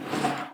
音素材 / SE
closet.wav